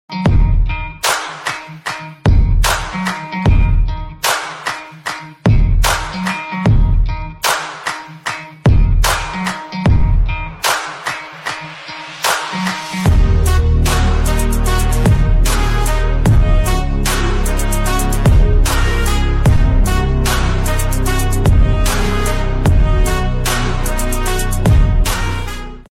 phonk